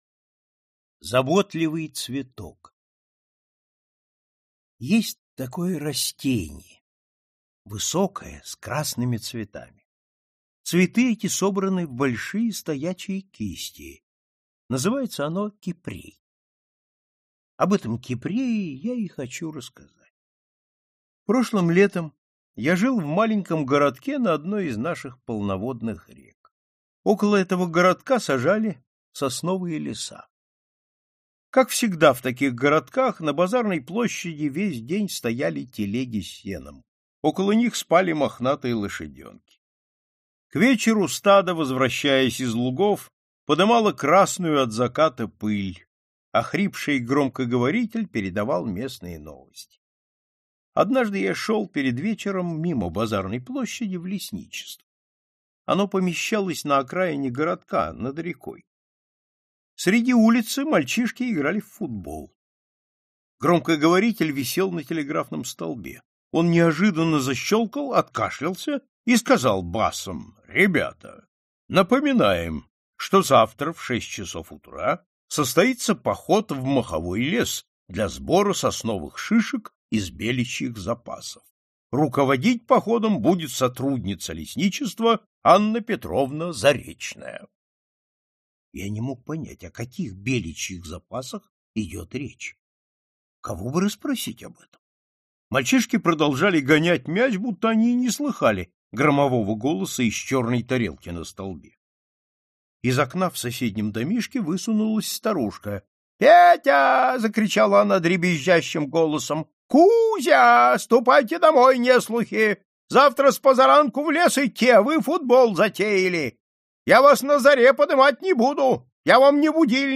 Аудиокнига Стальное колечко и другие сказки | Библиотека аудиокниг